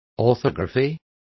Complete with pronunciation of the translation of orthography.